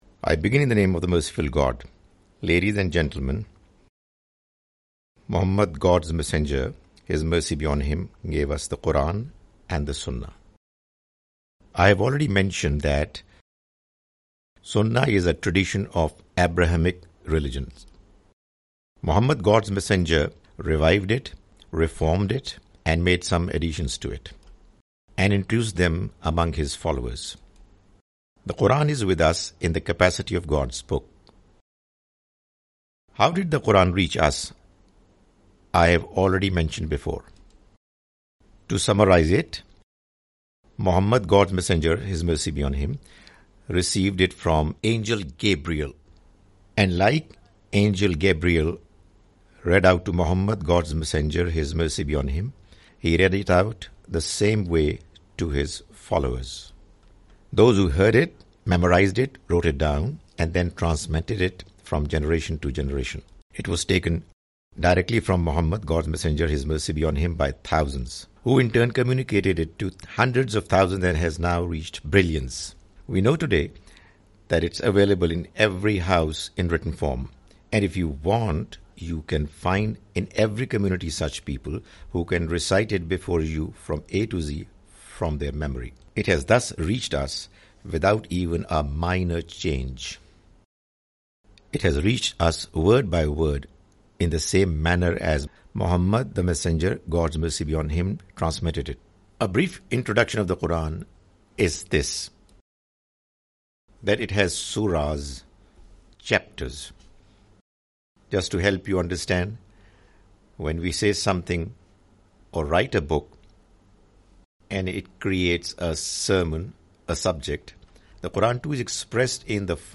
The Message of Qur'an (With English Voice Over) Part-8
The Message of the Quran is a lecture series comprising Urdu lectures of Mr Javed Ahmad Ghamidi.